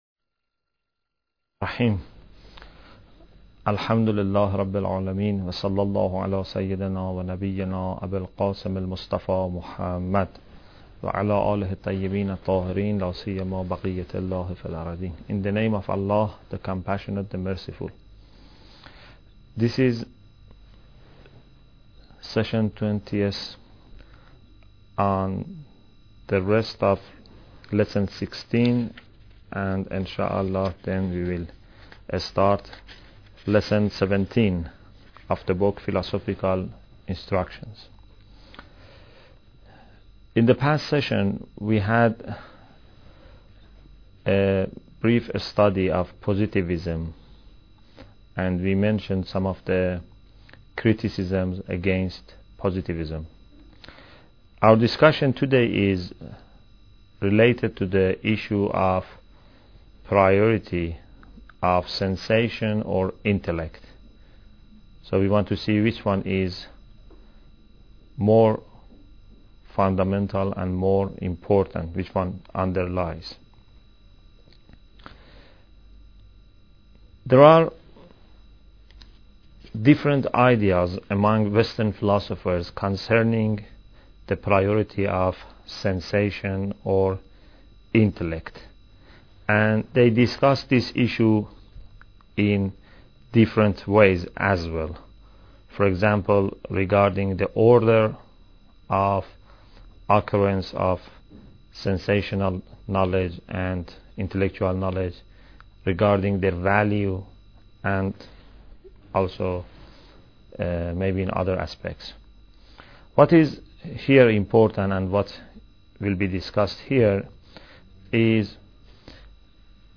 Bidayat Al Hikmah Lecture 20